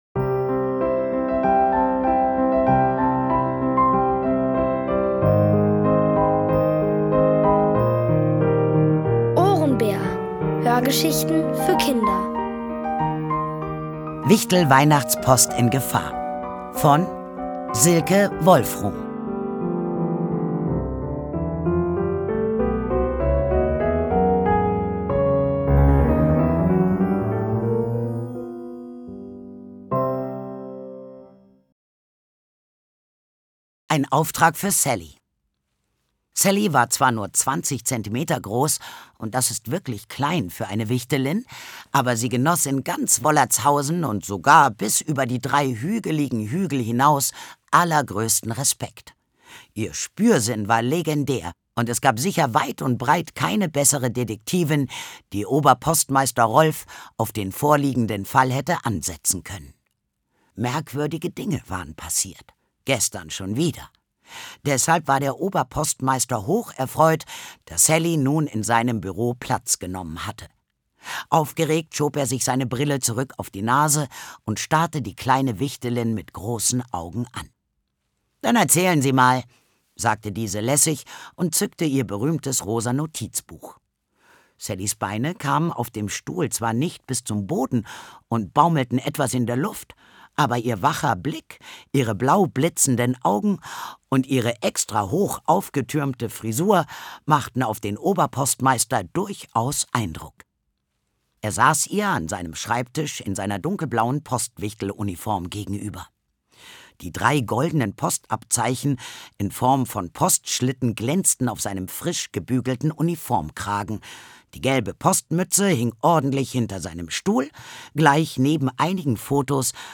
Von Autoren extra für die Reihe geschrieben und von bekannten Schauspielern gelesen.
Es liest: Sandra Schwittau.